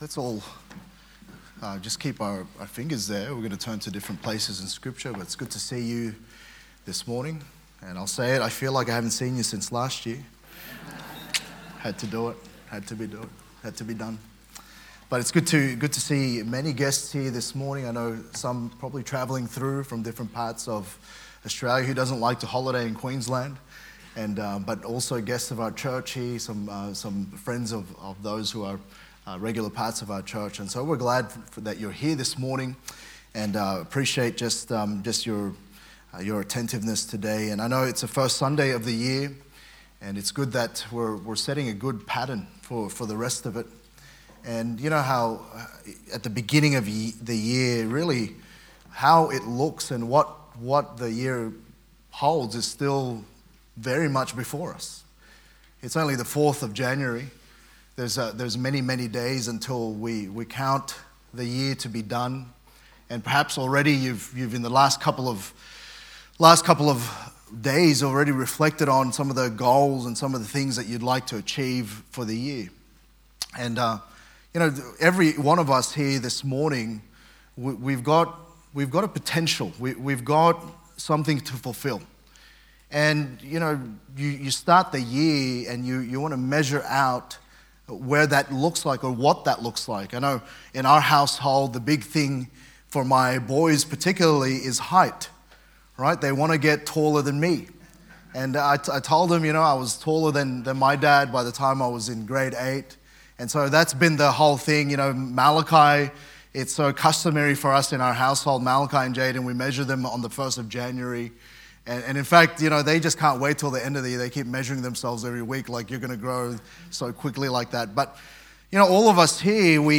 Sermons | Good Shepherd Baptist Church